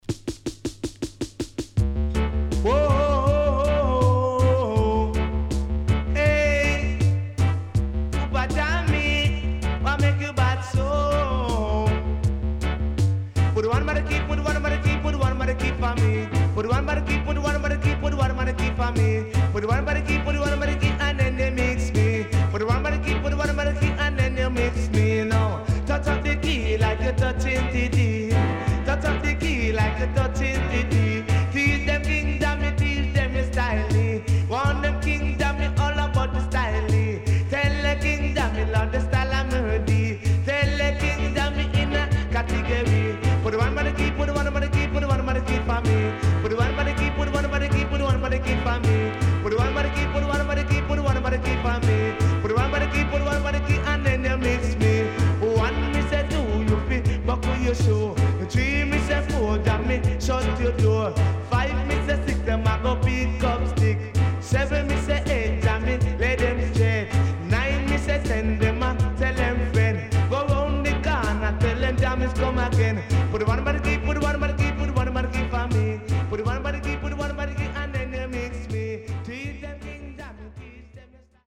HOME > REISSUE USED [DANCEHALL]
riddim
SIDE A:少しチリノイズ入りますが良好です。